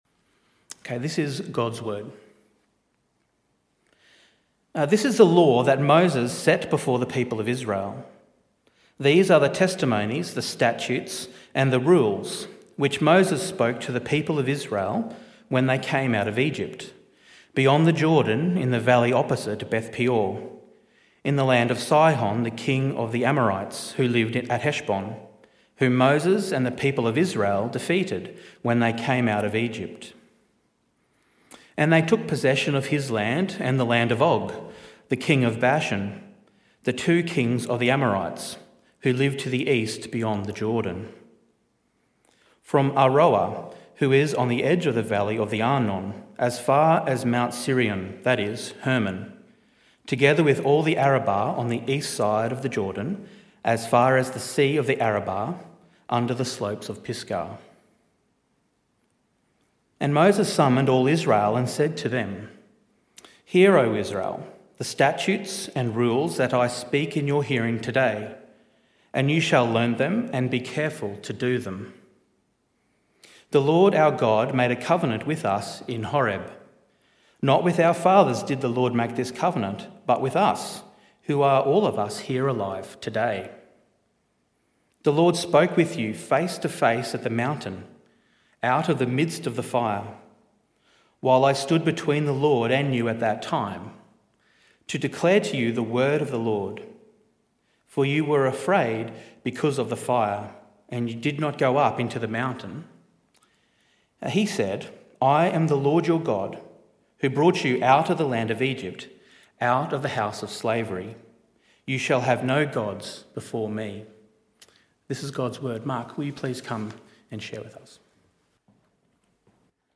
This talk was part of the AM Service series entitled Freedom: Understanding The 10 Commandments.